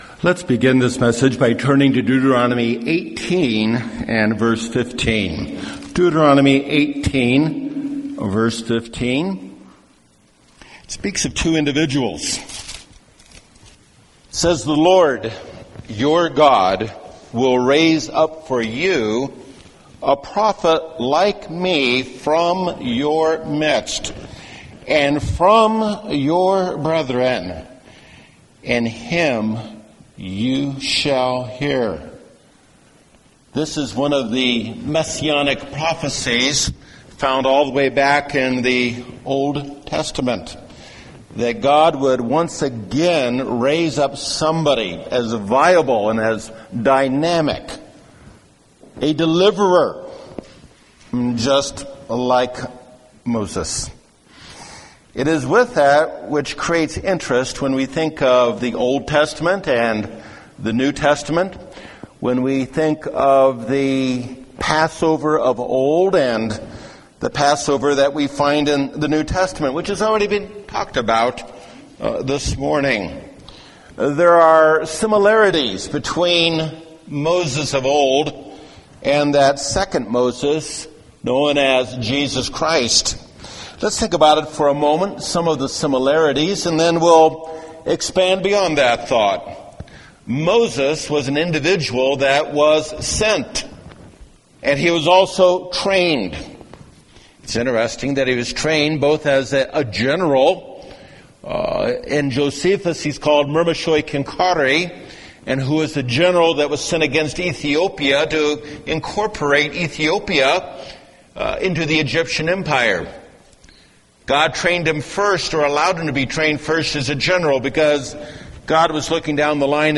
UCG Sermon Transcript